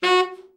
TENOR SN  23.wav